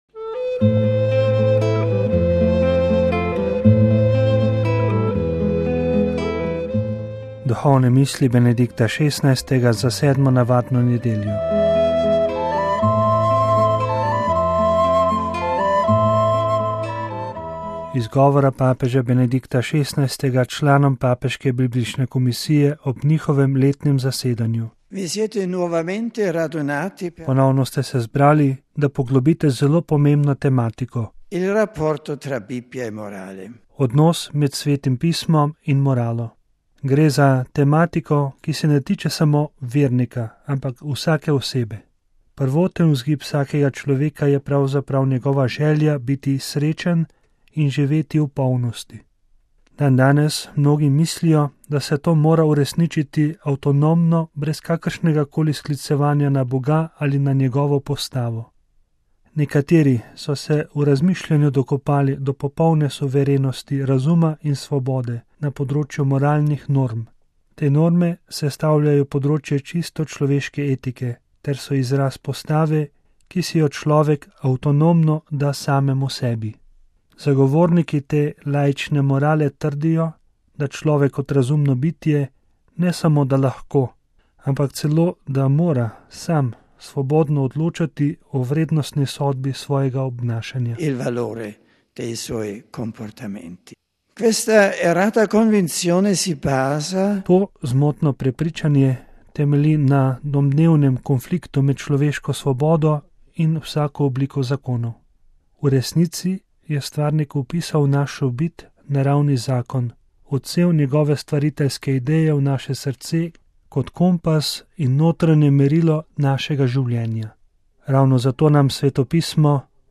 Iz govora papeža Benedikta XVI. članom papeške biblične komisije ob njihovem letnem zasedanju.